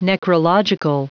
Prononciation du mot : necrological
necrological.wav